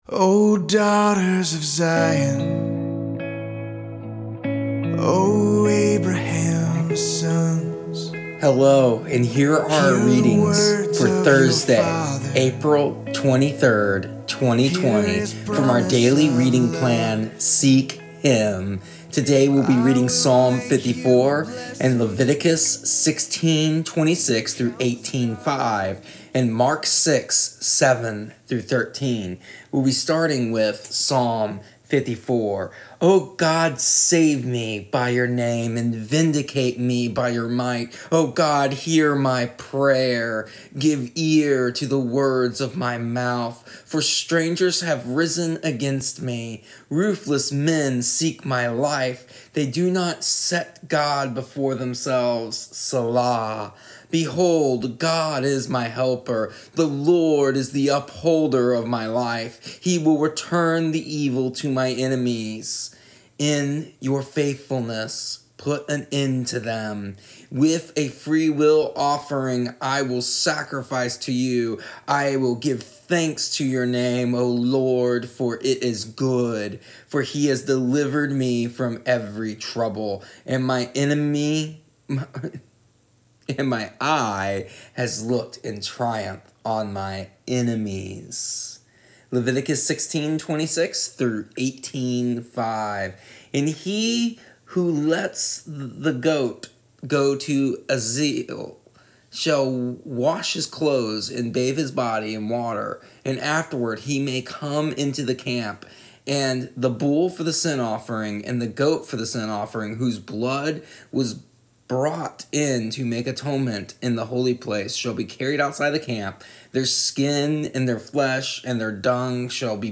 Here is our audio readings from our daily reading plan Seek Him for April 23rd, 2020. Today we discuss the reality of how God is holy and how He has called us to be the same, and what this means and looks like to be set a part.